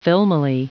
Prononciation du mot filmily en anglais (fichier audio)
Prononciation du mot : filmily